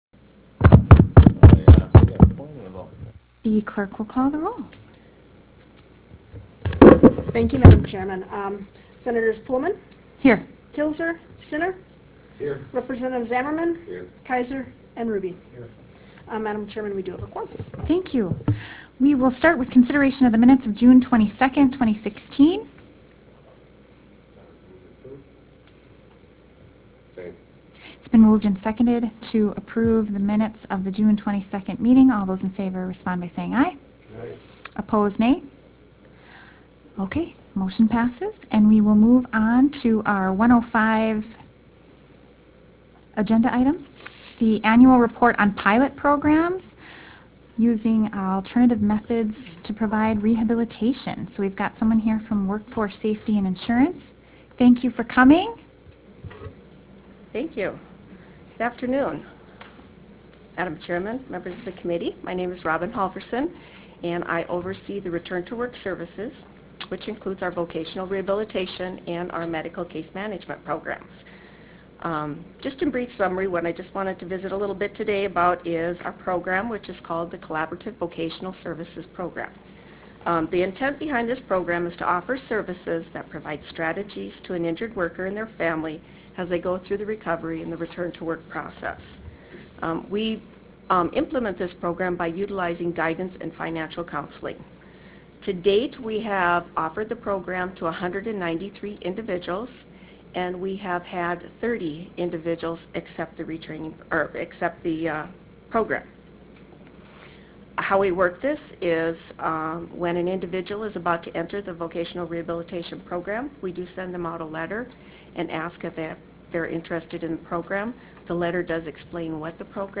Lewis and Clark Room State Capitol Bismarck, ND United States